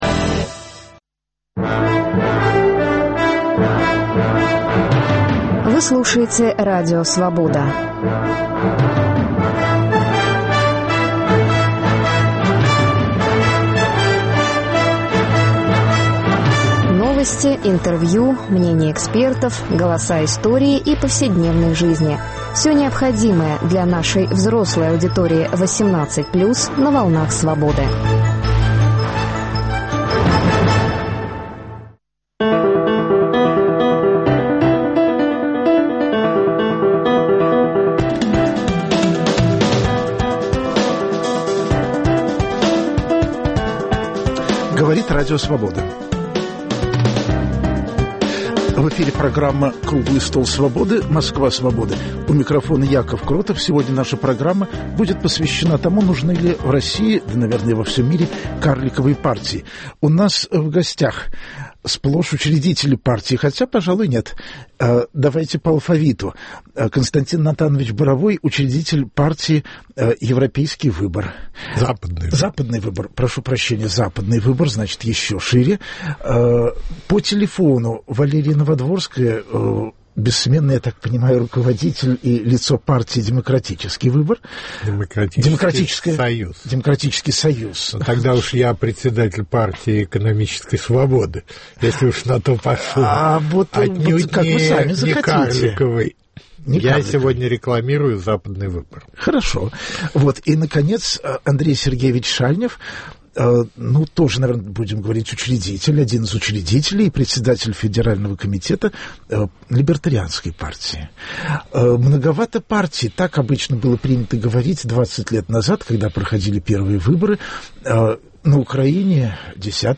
Круглый стол: Москва Свободы